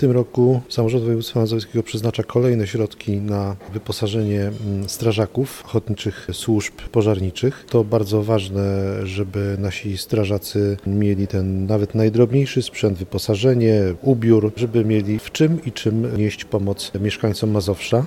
Wicemarszałek województwa mazowieckiego, Wiesław Raboszuk podkreśla, jak bardzo istotna jest pomoc finansowa dla strażaków.